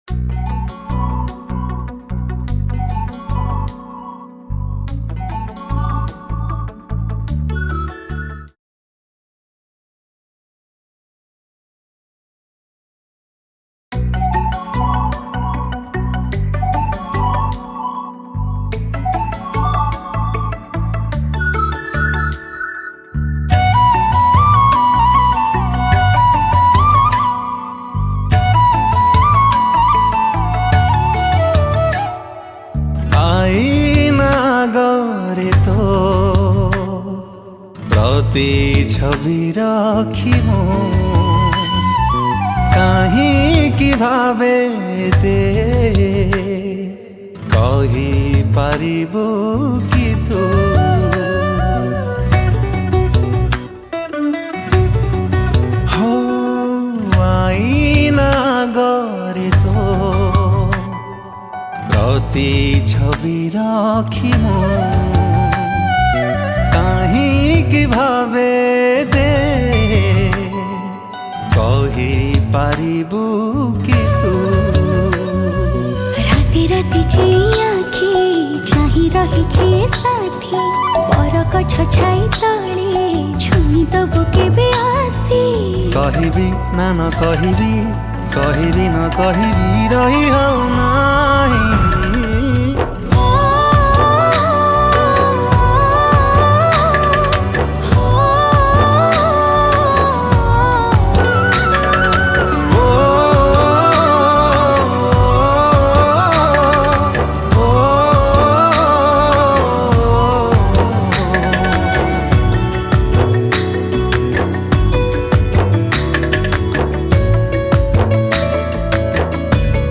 Flute